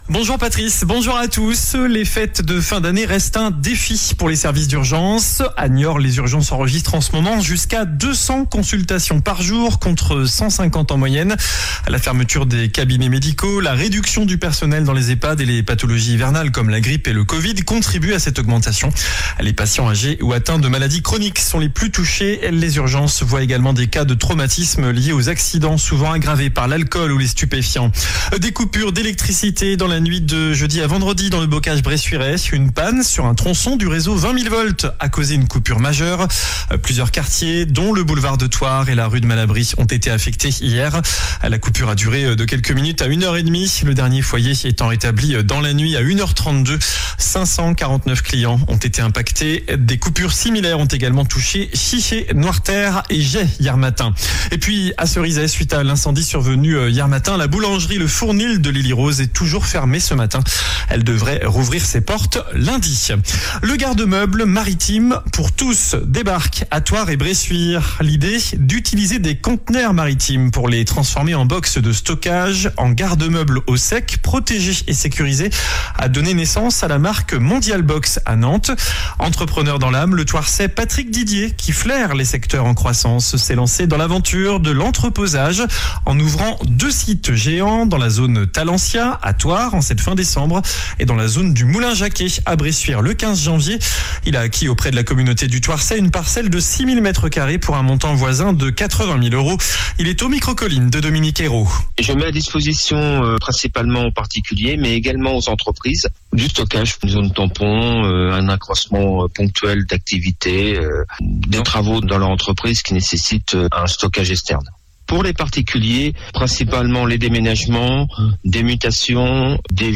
JOURNAL DU SAMEDI 28 DECEMBRE